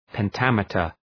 Προφορά
{pen’tæmıtər}